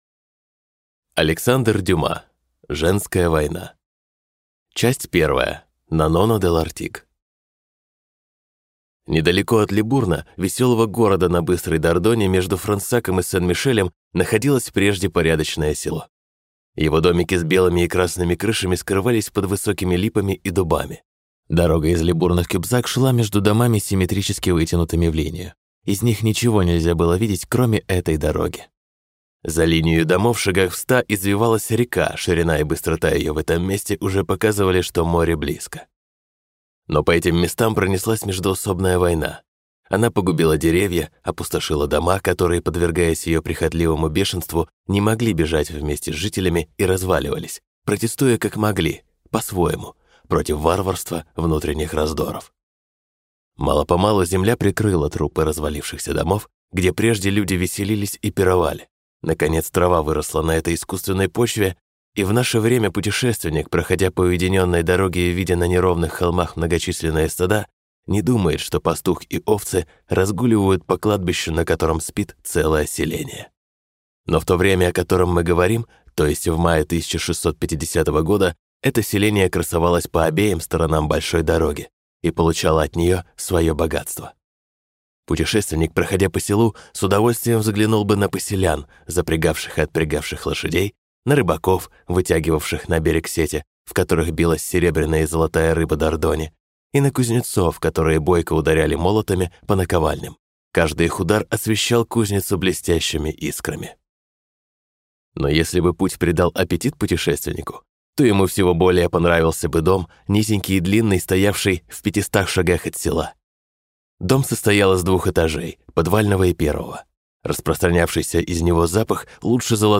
Аудиокнига Женская война | Библиотека аудиокниг